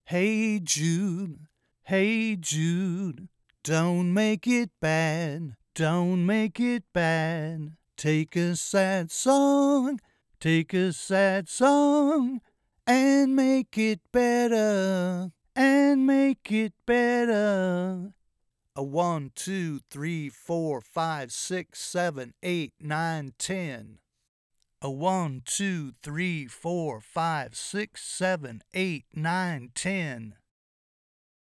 Attachments U87ai PG420 clips MP3 copy.mp3 U87ai PG420 clips MP3 copy.mp3 1.2 MB